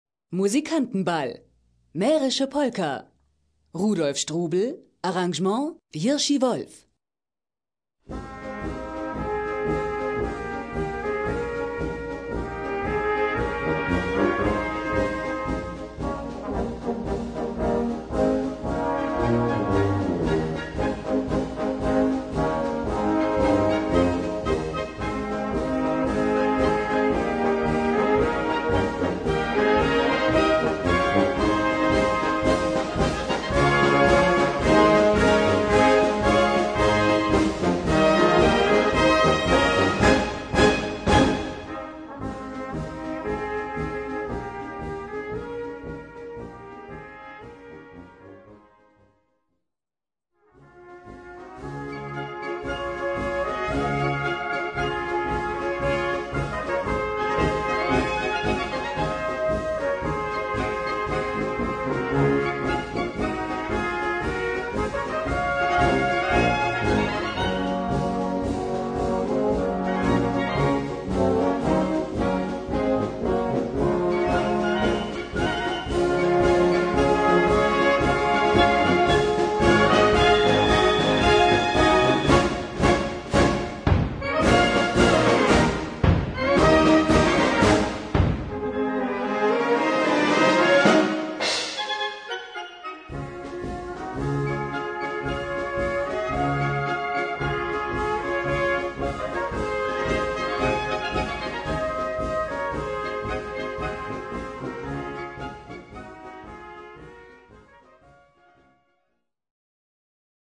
Gattung: Mährische Polka
Besetzung: Blasorchester